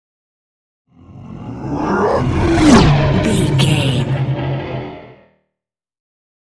Horror creature vehicle pass by
Sound Effects
Atonal
scary
ominous
eerie